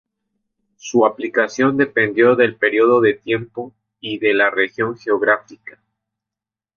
Uitgesproken als (IPA)
/aplikaˈθjon/